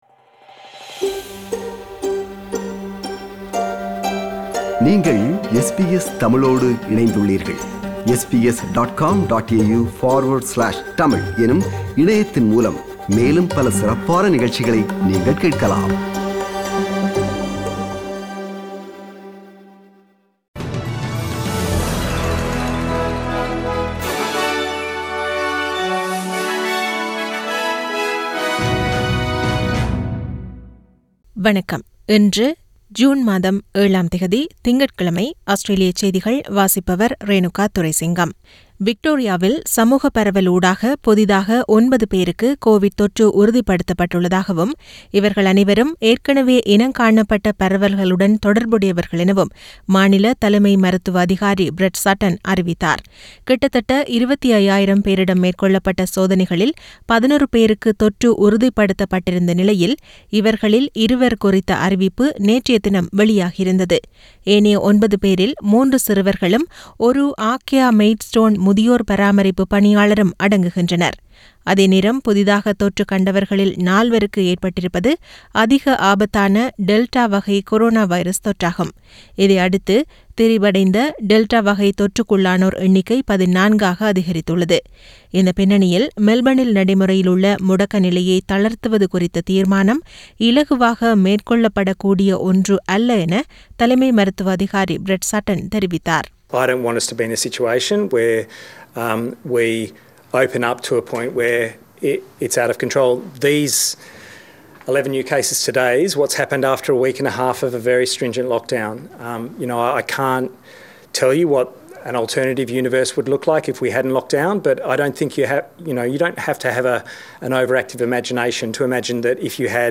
Australian news bulletin for Monday 07 June 2021.